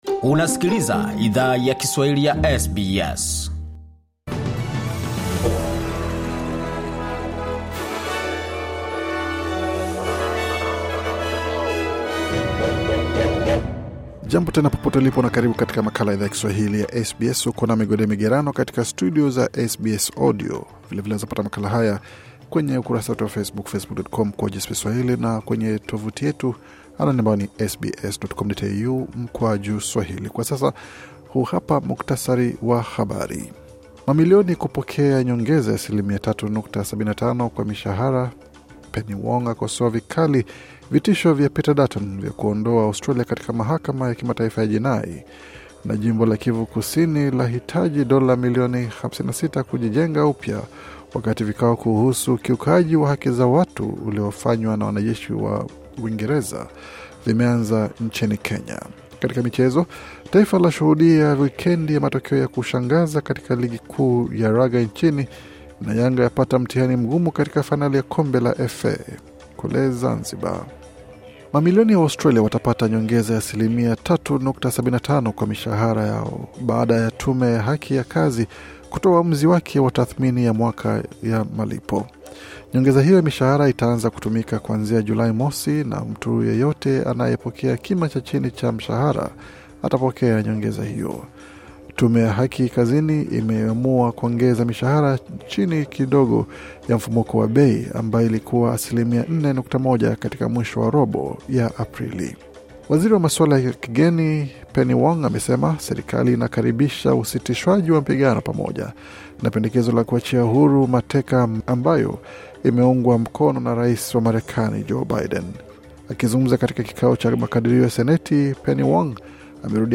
Taarifa ya Habari 3 Juni 2024